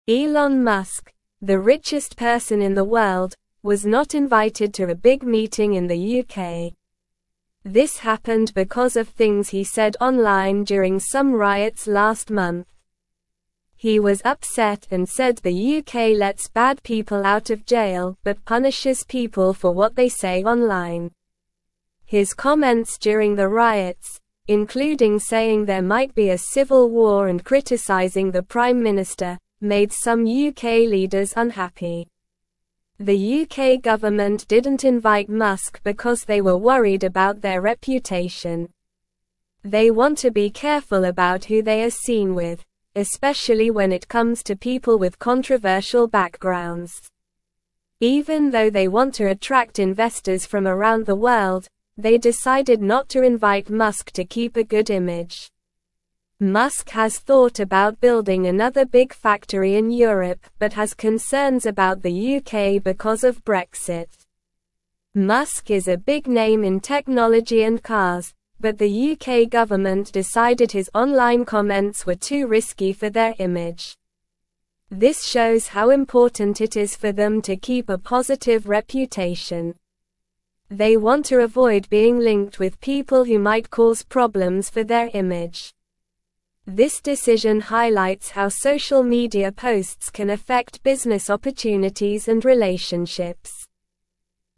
Slow
English-Newsroom-Lower-Intermediate-SLOW-Reading-Elon-Musk-not-invited-to-UK-meeting-upset.mp3